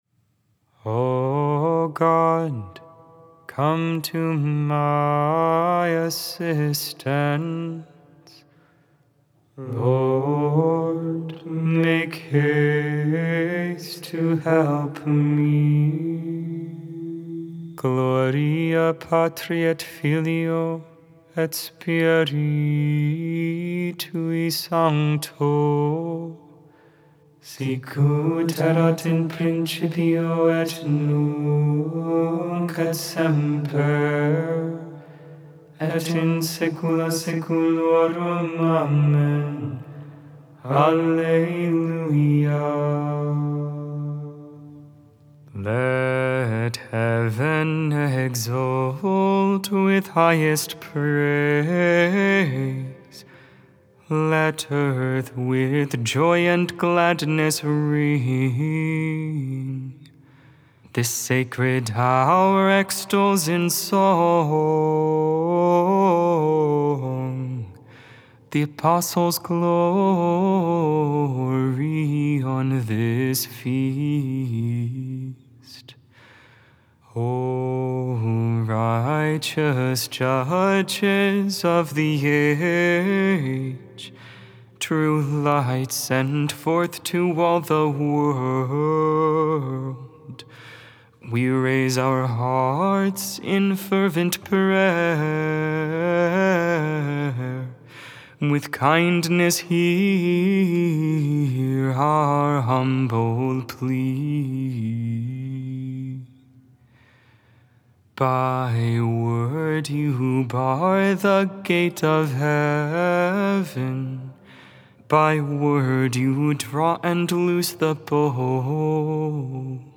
Vespers, Evening Prayer on the 30th Friday in Ordinary Time, October 28th, 2024. Feast of St. Simon and St. Jude.